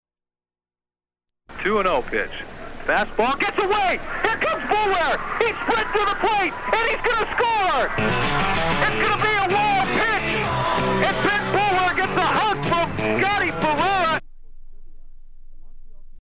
Clip of a play-by-play call of a Cal Poly sports event.
Form of original Audiocassette